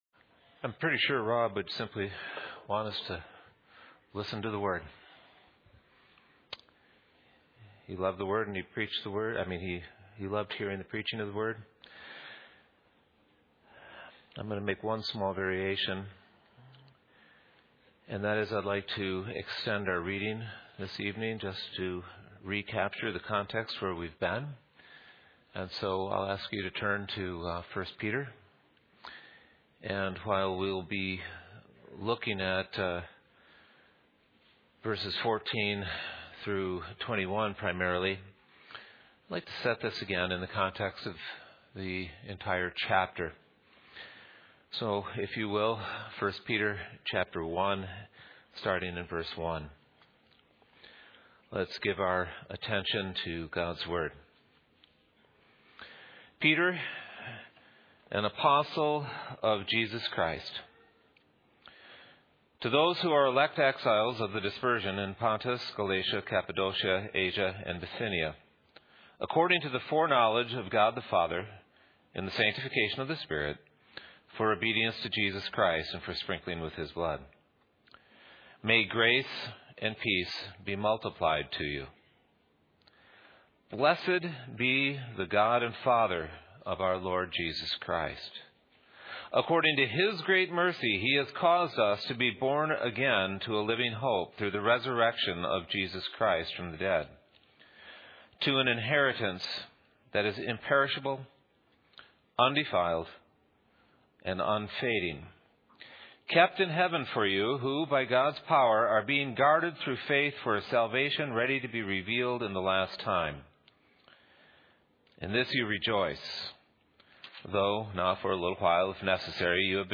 Passage: 1 Peter 3:8-12 Service Type: Evening